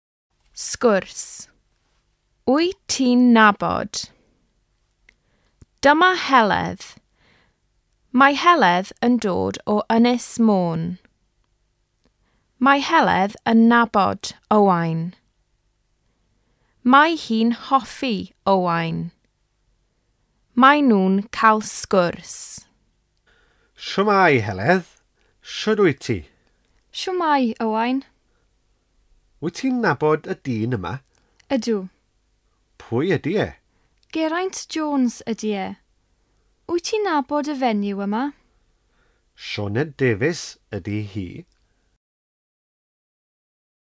Lektionstexte, Aussprache- und Hörverständnisübungen aus dem "Lehrbuch der walisischen Sprache".